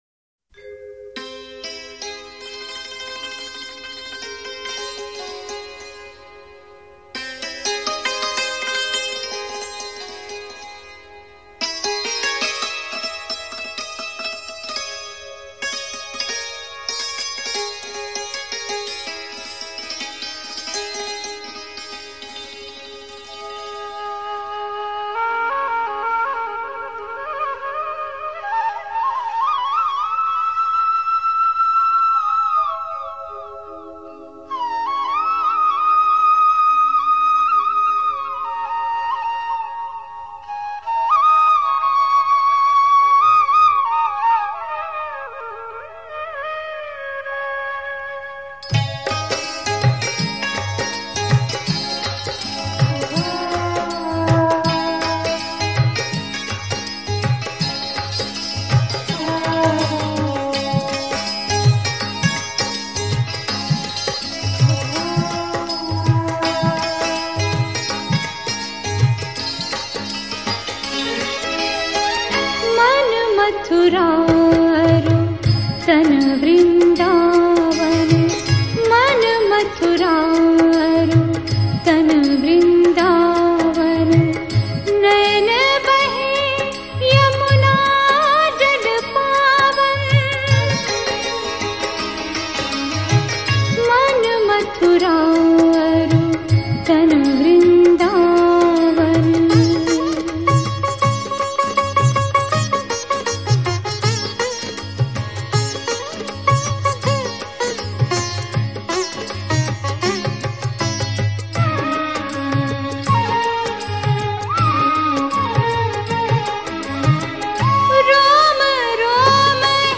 Shree Krishna Bhajans